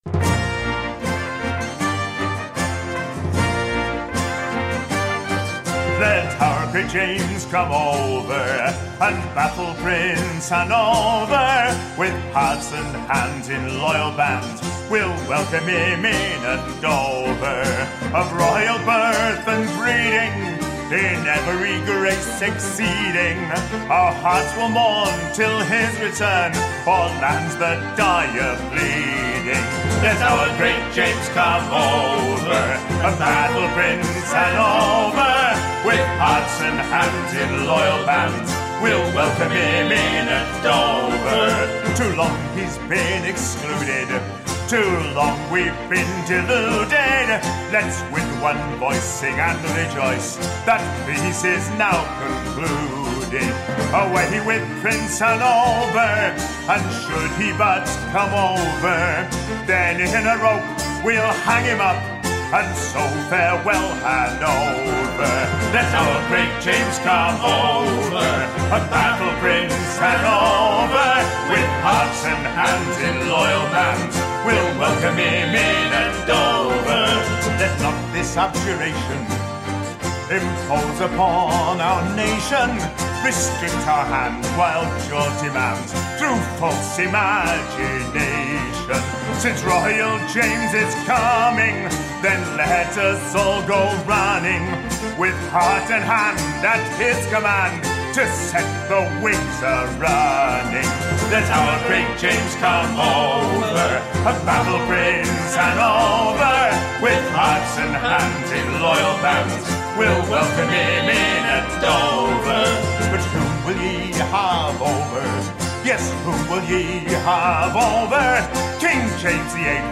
Full Stereo Version